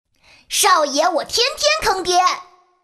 国语少年素人 、女动漫动画游戏影视 、看稿报价女游11 国语 女声 游戏 王者荣耀角色模仿-5刘禅 (2) 素人